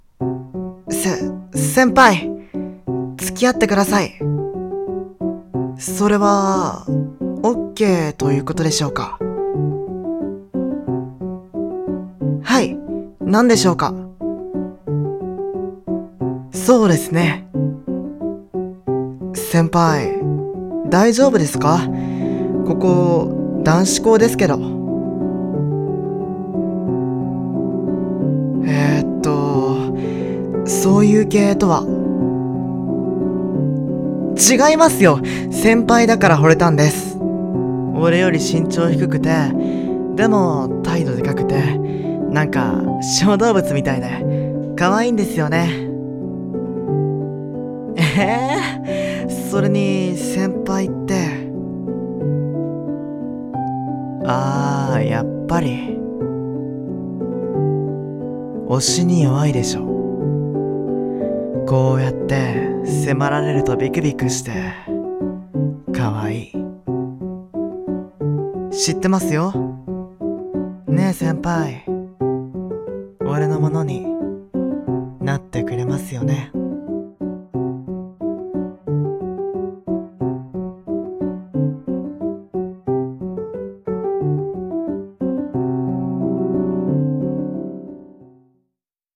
【BL声劇】俺の可愛い先輩【二人声劇】